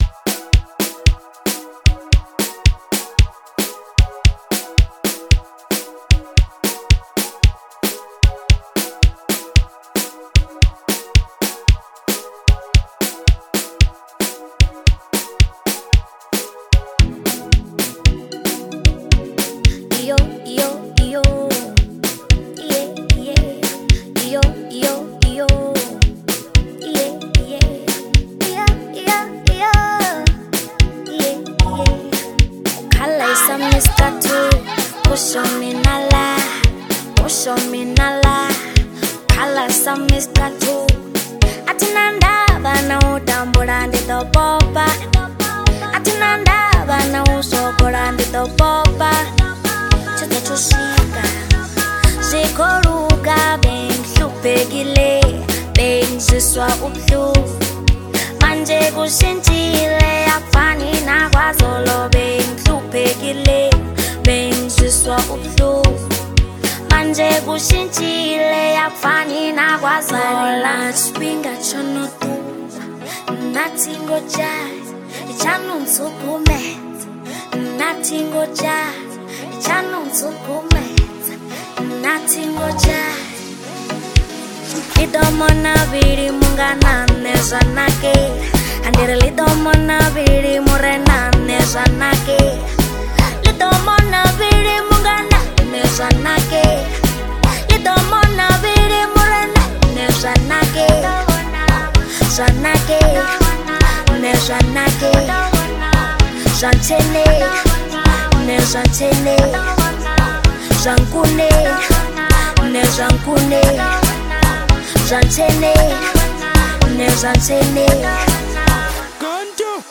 • Genre: Amapiano